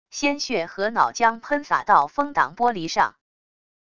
鲜血和脑浆喷洒到风挡玻璃上wav下载